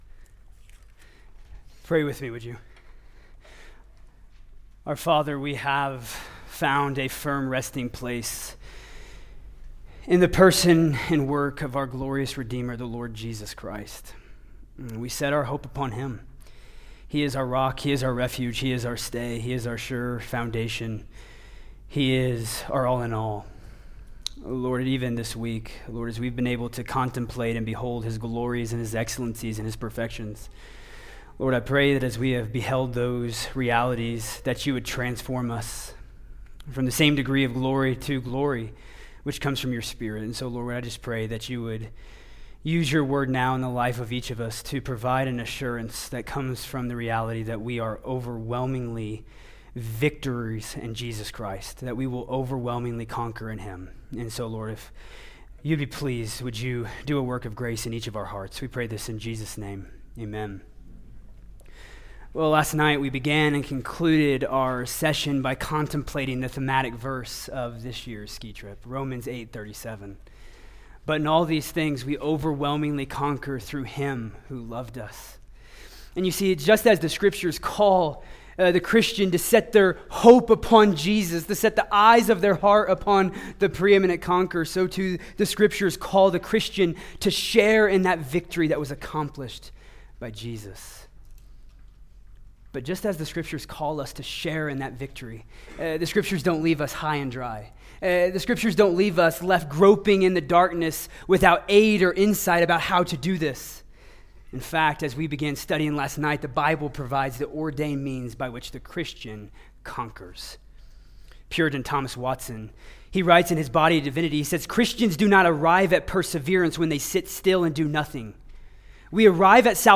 College/Roots Roots Winter Retreat - 2025 Audio ◀ Prev Series List Previous 2.